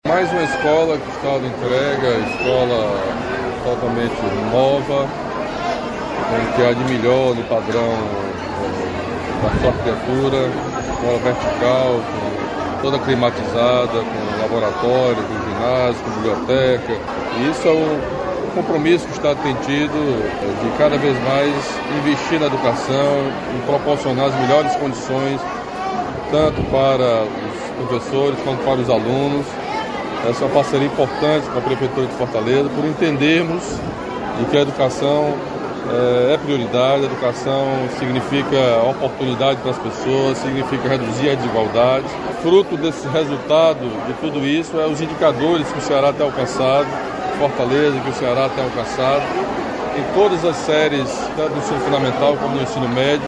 Nova sede da Escola de Ensino Médio Deputado Manoel Rodrigues é inaugurada em Fortaleza
Entusiasta da educação pública como mecanismo de transformação da sociedade, o governador Camilo Santana disse que investir na área significa justiça social.